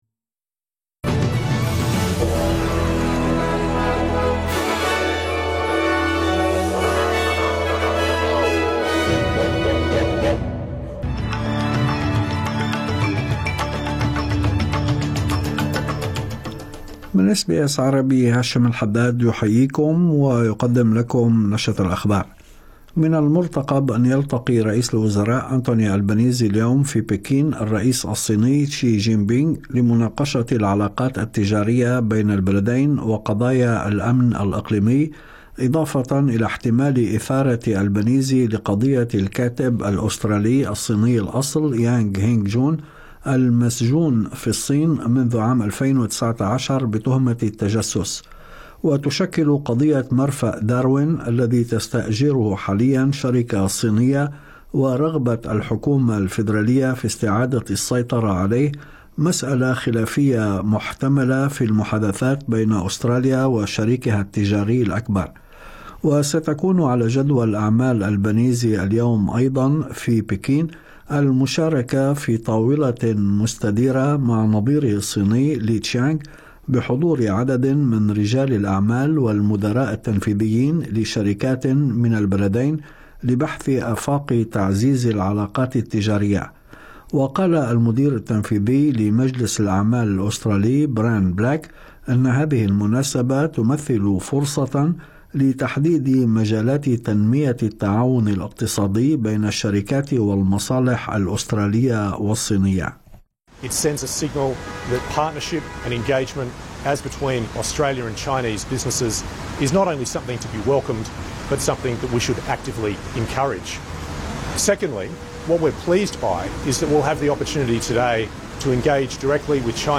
نشرة أخبار الظهيرة 15/7/2025